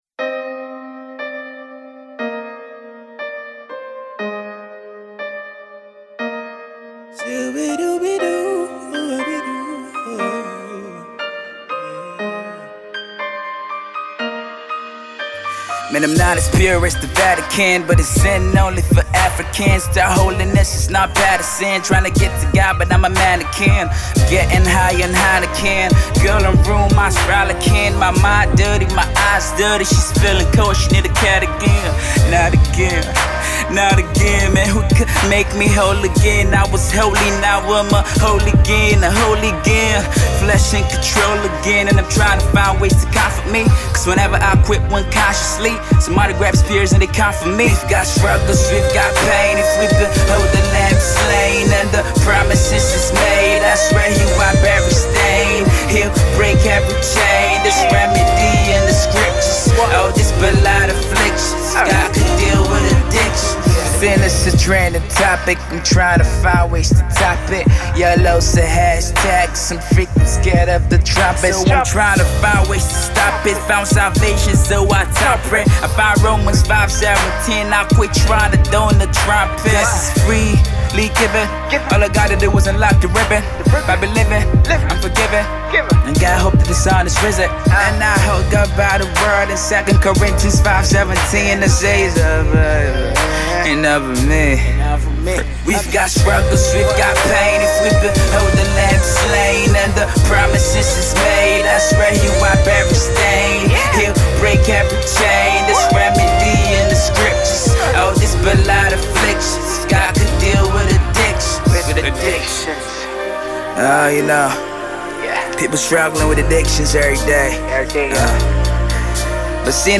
AudioGospelRap